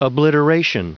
Prononciation du mot obliteration en anglais (fichier audio)
Prononciation du mot : obliteration